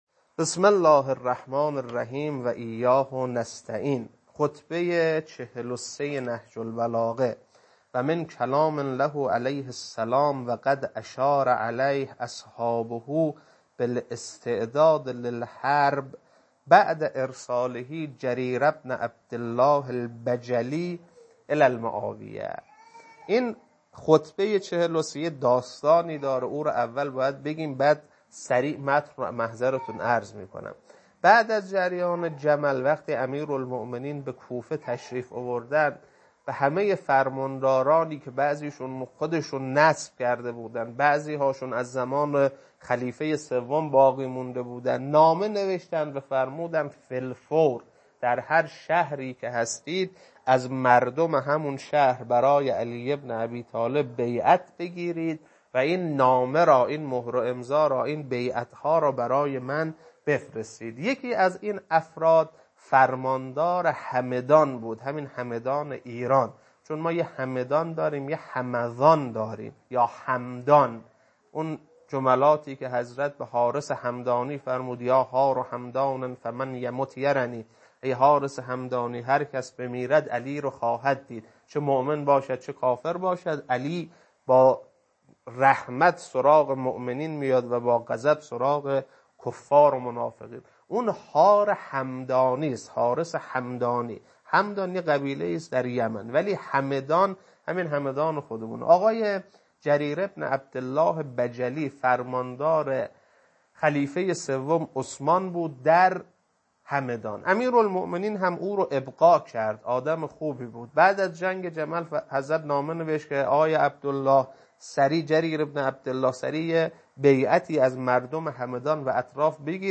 خطبه 43.mp3